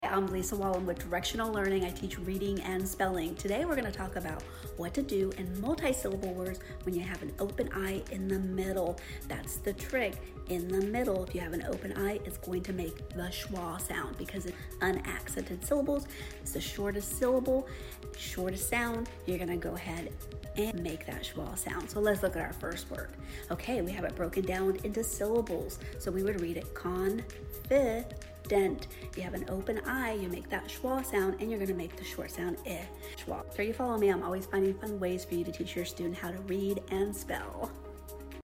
Don't make this mistake when pronouncing words with open i in the middle syllable. Learn about the Schwa Sound.